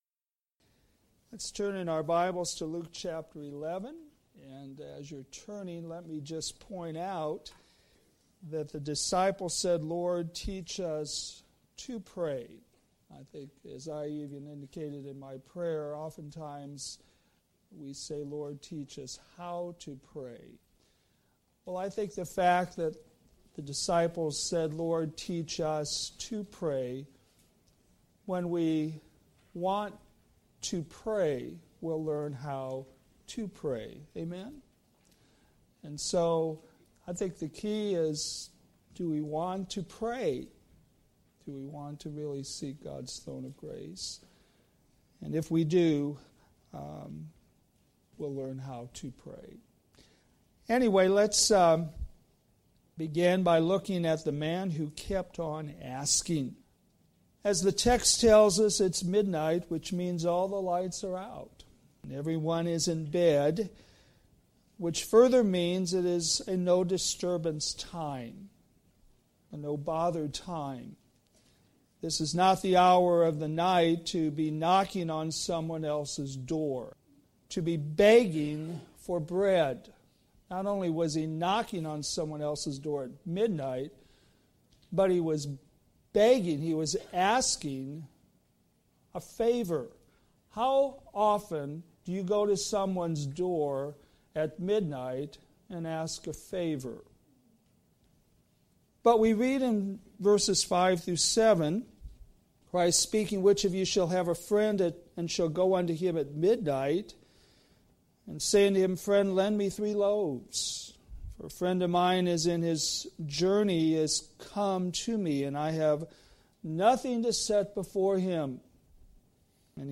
Sermons based on New Testament Scripture